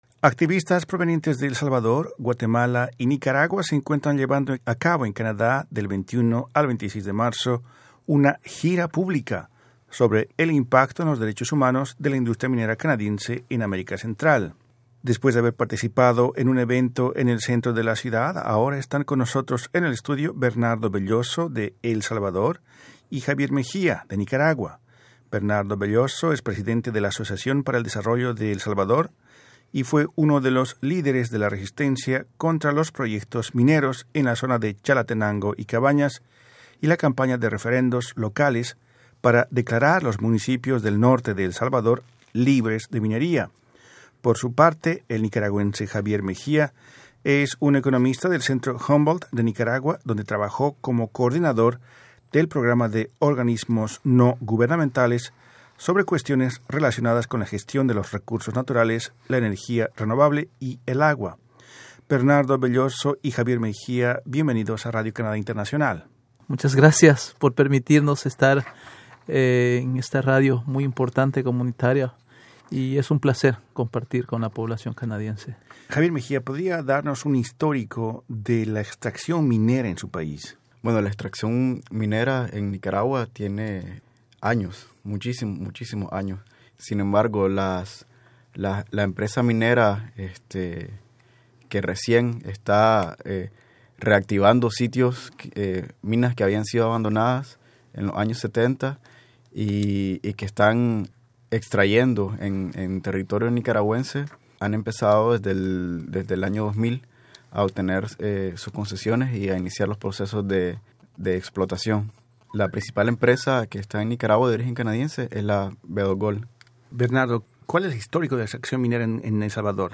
pudieron conversar con Radio Canadá Internacional